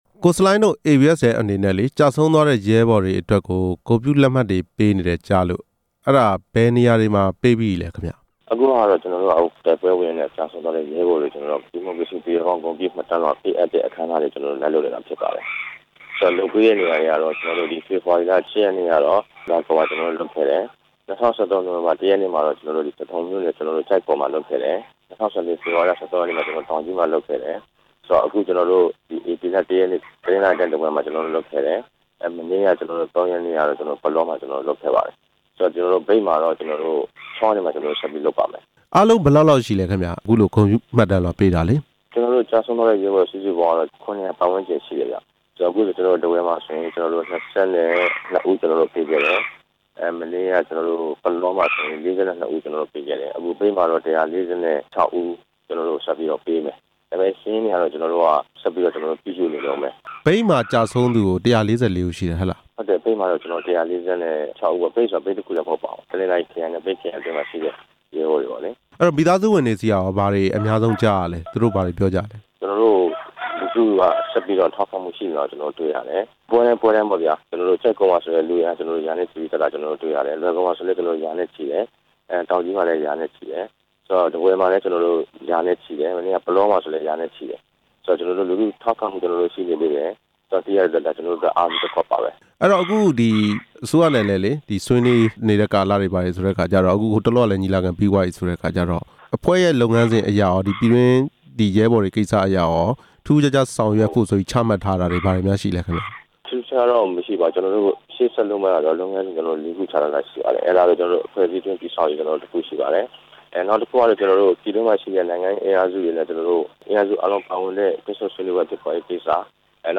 ABSDF ဂုဏ်ပြုမှတ်တမ်းလွှာ ပေးအပ်တဲ့အကြောင်း မေးမြန်းချက်